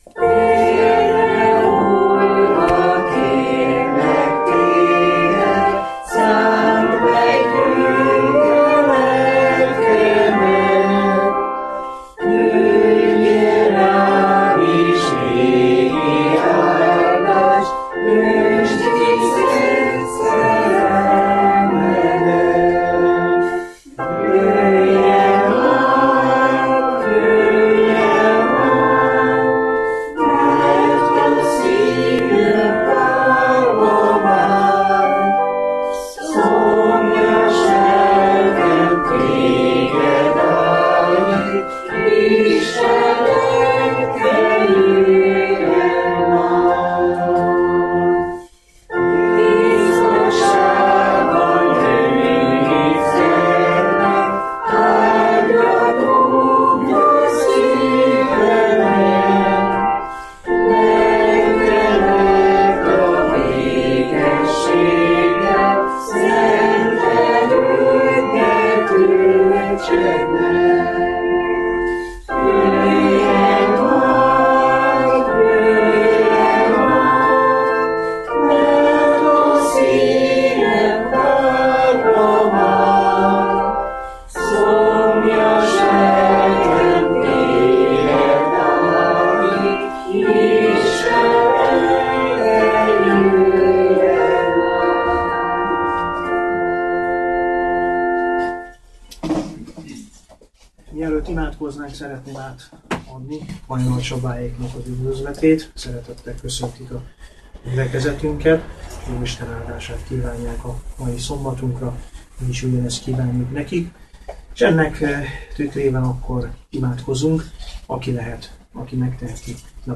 igehirdetés Zsoltárok könyve, 107. zsoltár